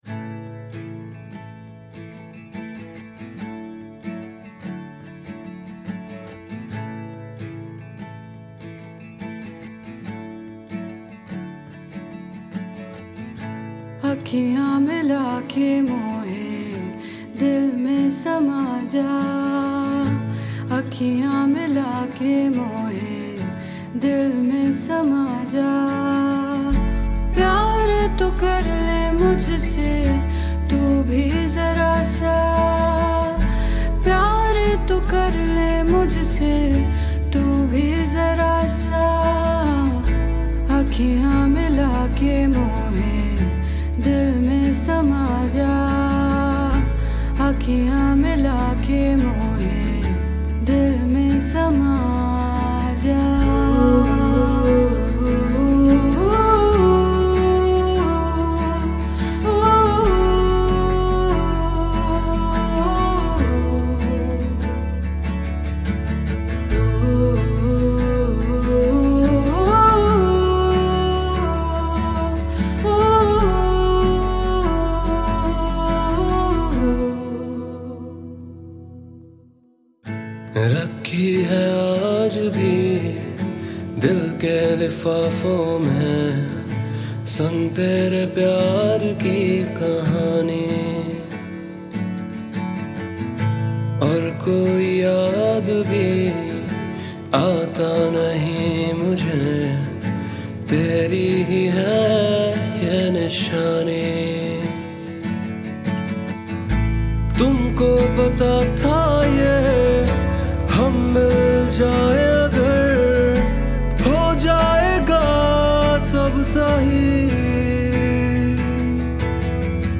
IndiPop Music Album